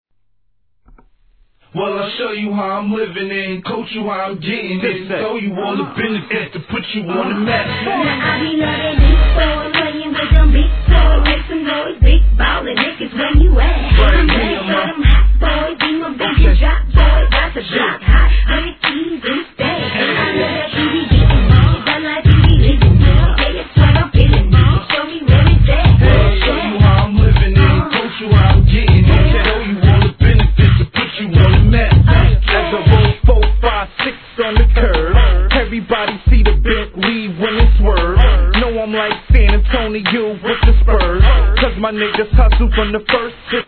HIP HOP/R&B
ZUM ZUMなるドラムラインに男性の吠えるような声のループ(途中曲調が変わるのもツボ）